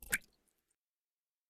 Bullet Shell Sounds
generic_water_8.ogg